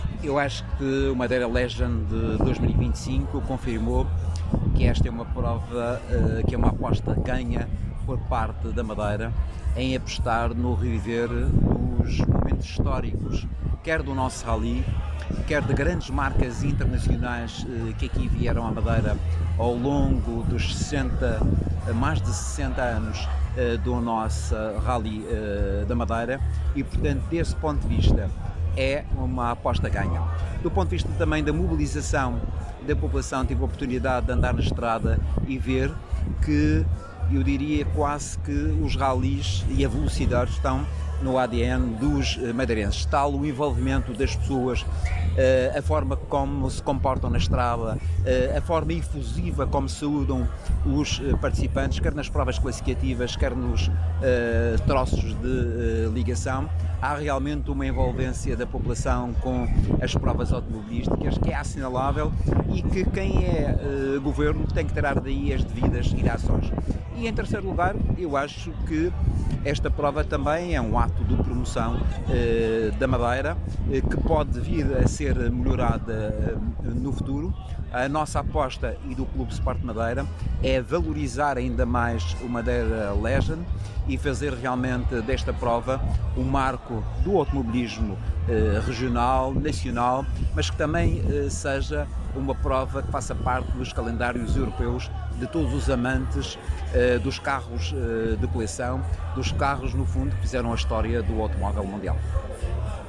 As declarações de José Manuel Rodrigues foram proferidas durante a cerimónia de entrega de prémios, na Praça CR7, no Funchal, ocasião em que voltou a destacar, também, a dimensão estratégica da prova, reiterando aquilo que já havia afirmado na cerimónia de partida: " O Madeira Legend é um ato de
Secretarioda Economia_JMR_Entrega de Prémios_ Rally _Legend2025.mp3